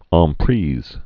(än prēz, äɴ)